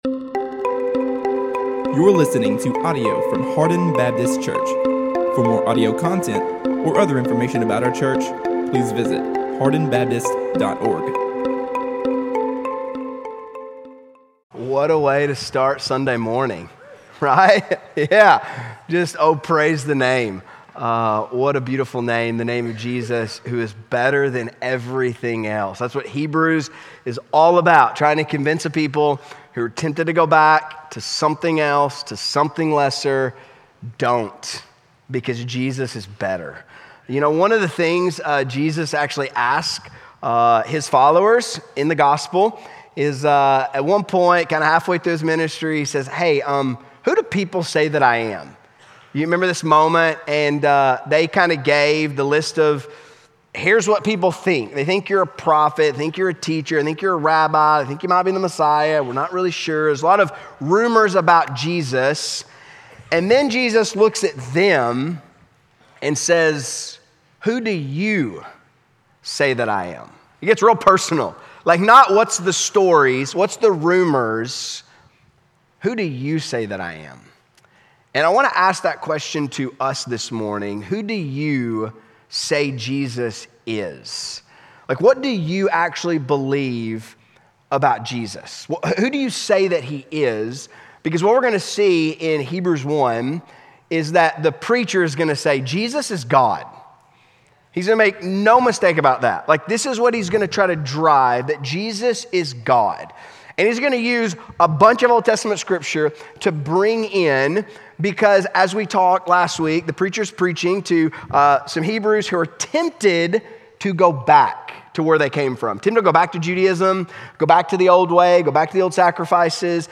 A message from the series "Youth."